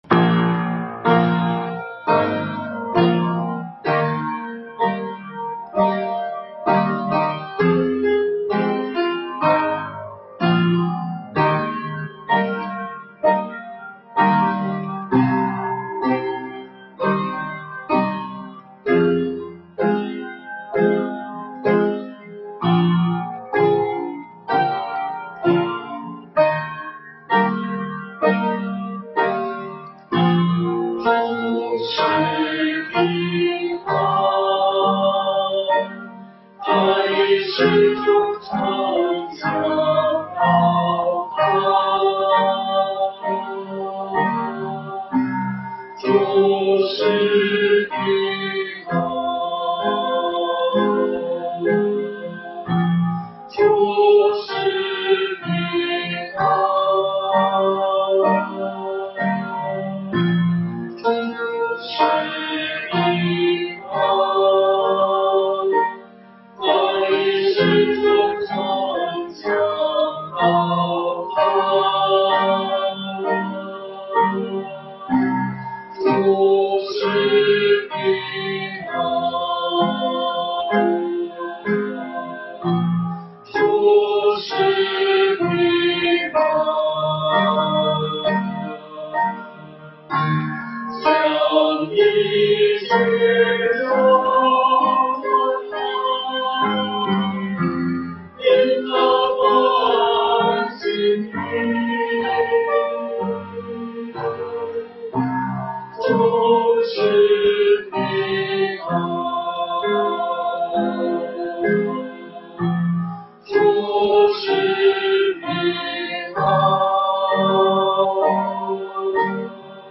人子与枯干的手 | 北京基督教会海淀堂